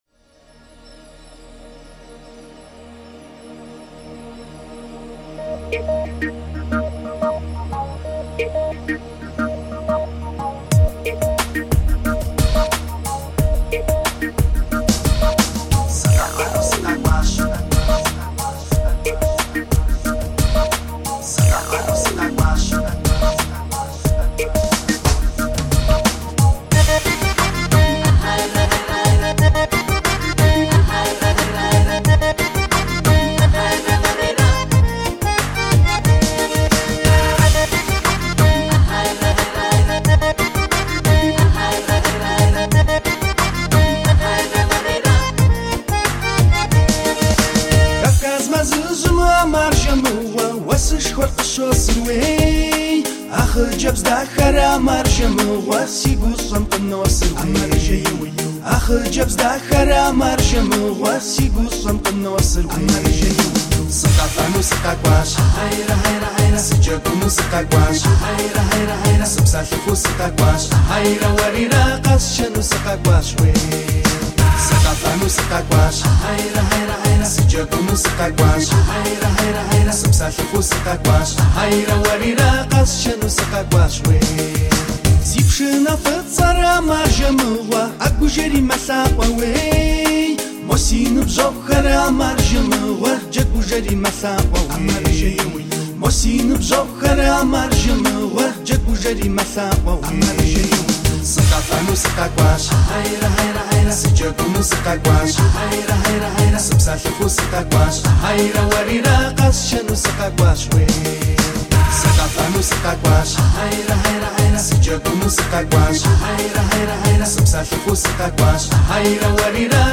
Mahalli Müziler